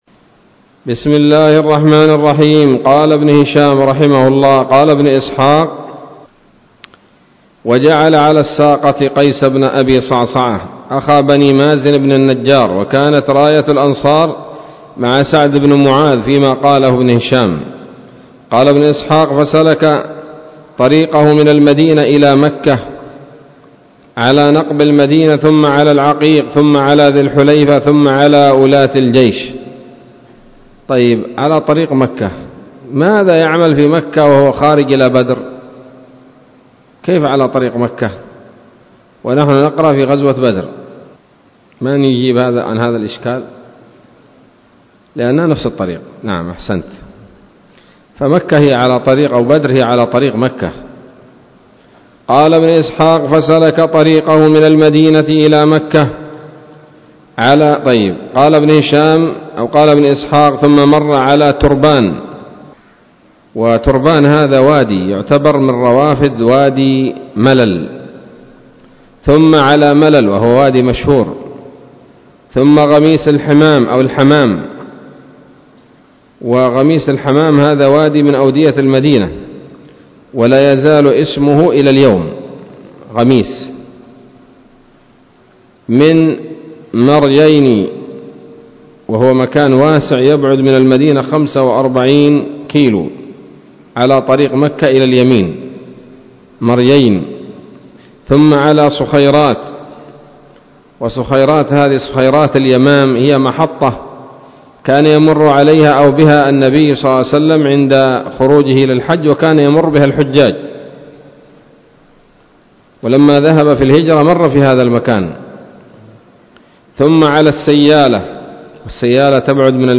الدرس العاشر بعد المائة من التعليق على كتاب السيرة النبوية لابن هشام